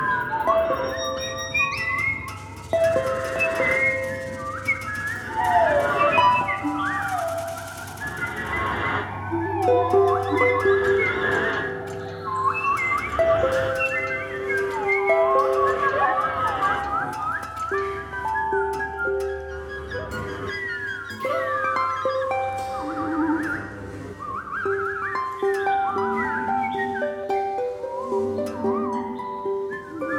clar.
Musiques d'influence afro-américaine - Jazz